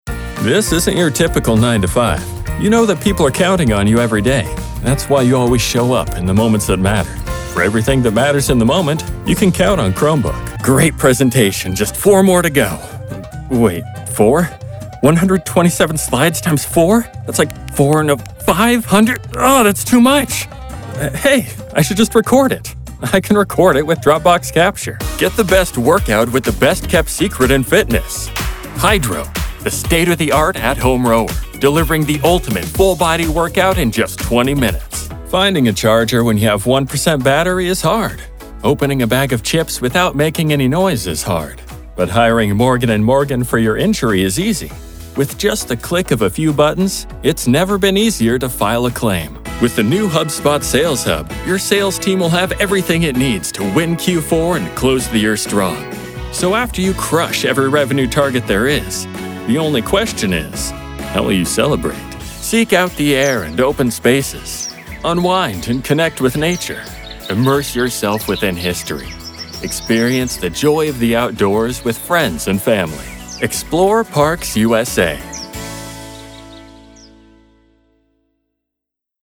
Story-driven voice actor providing broadcast quality voice over for video games, animation, commercials, E-learning, narration, promotional videos, and more.
Commercial demo